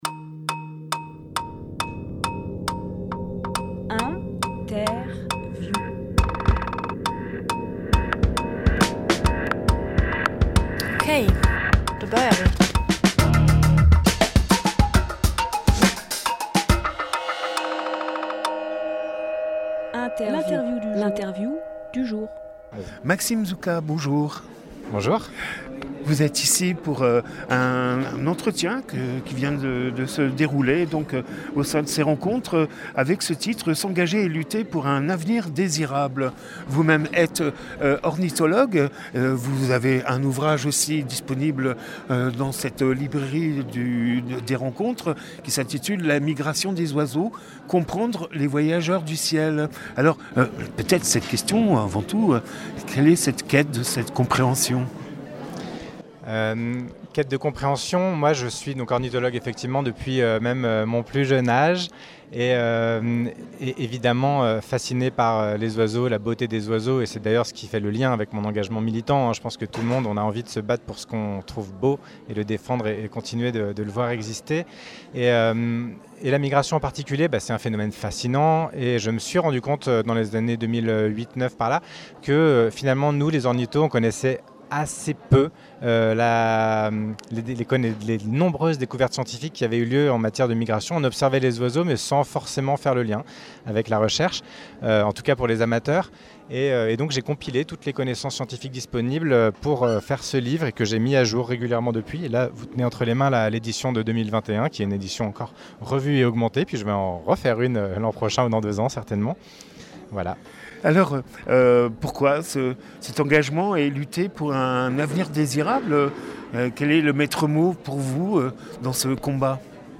Interview Coopérer pour un territoire vivant
lieu : Salle polyvalente